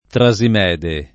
Trasimede [ tra @ im $ de ]